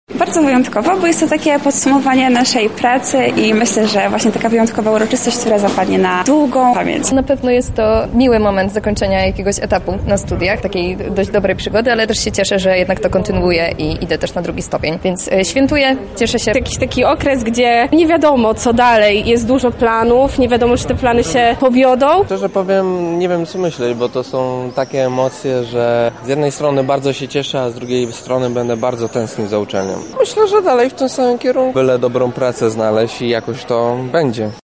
Zapytaliśmy studentów i studentki, jakie są ich dalsze plany.
Studenci podzielili się z nami emocjami, które im towarzyszą.
Studenci